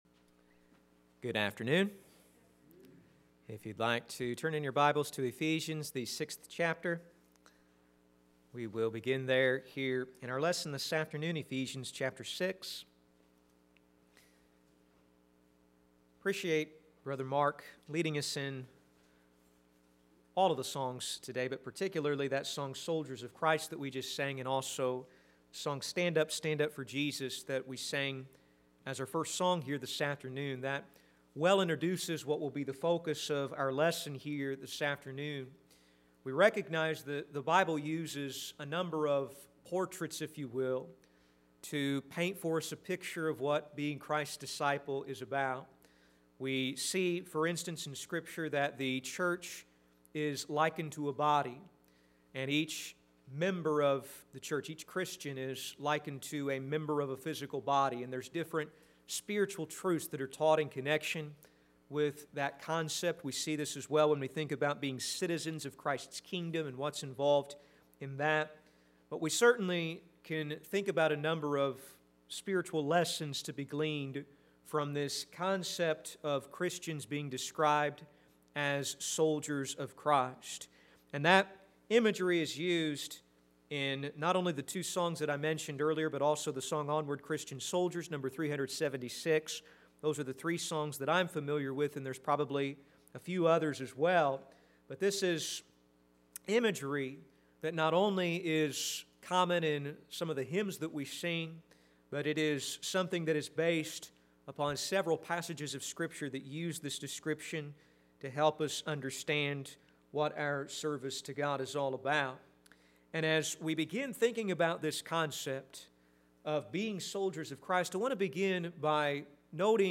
Sermons - Olney Church of Christ
Service: Sunday AM